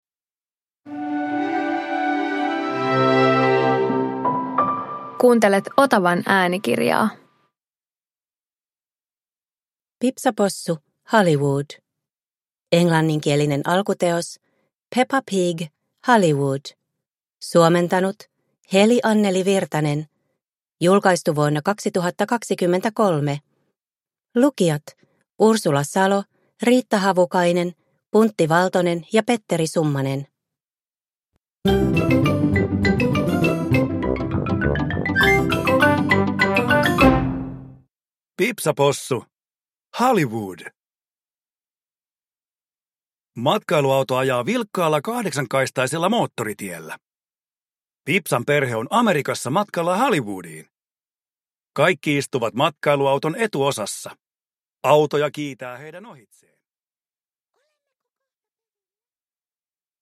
Pipsa Possu - Hollywood – Ljudbok